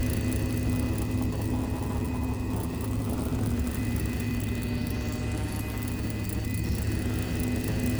Engine 5 Loop.wav